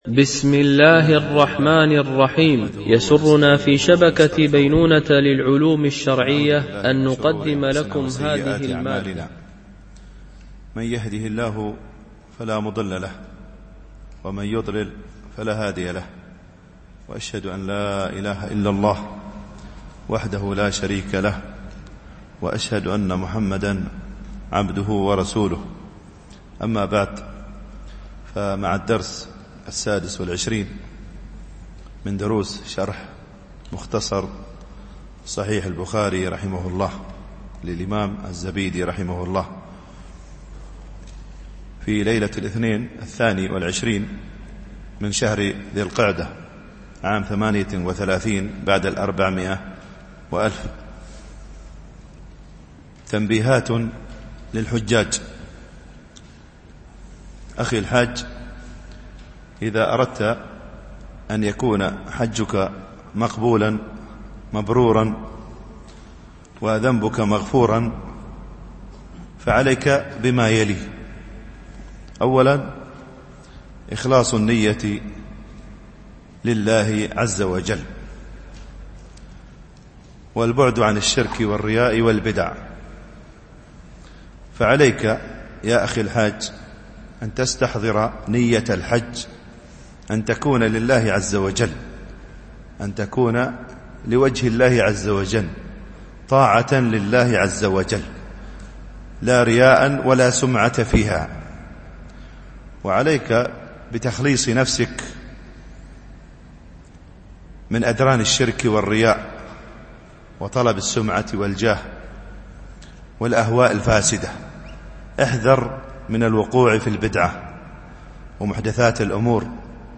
شرح مختصر صحيح البخاري ـ الدرس 26 (الحديث 79 - 84)